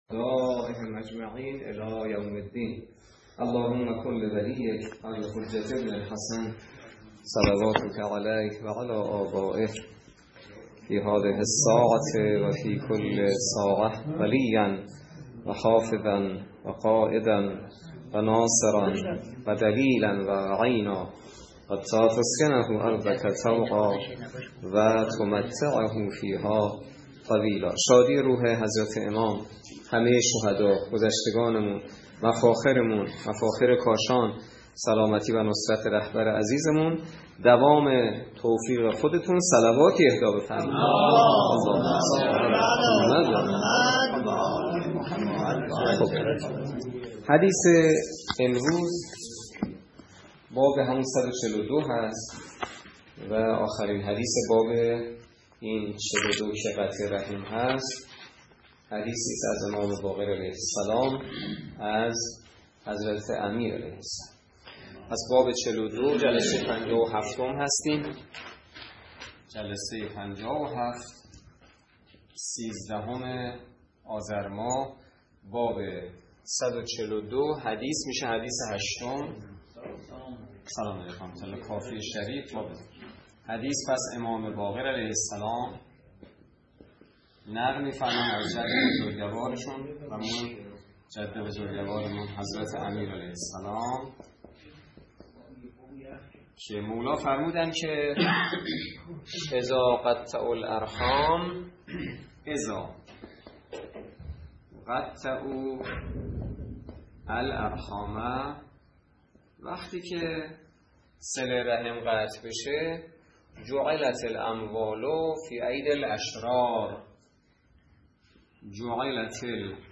درس فقه الاجاره نماینده مقام معظم رهبری در منطقه و امام جمعه کاشان - سال سوم جلسه پنجاه و هفت